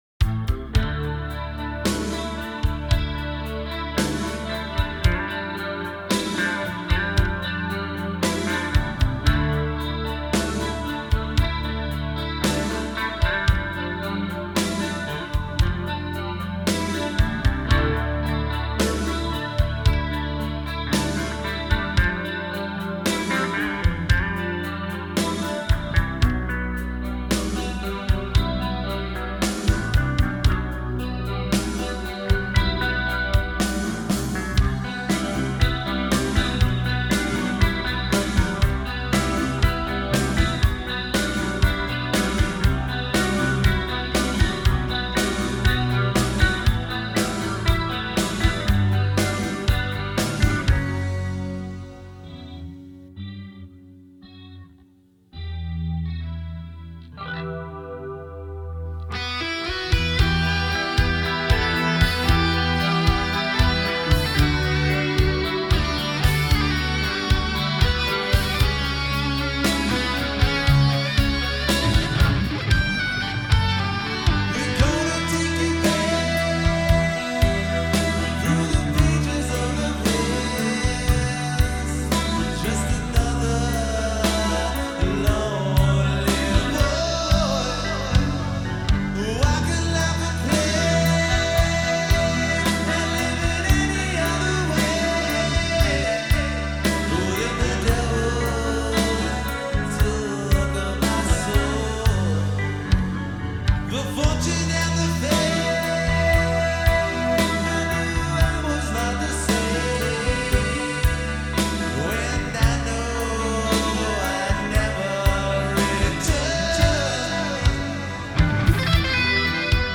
1990 Genres: Heavy metal, Thrash metal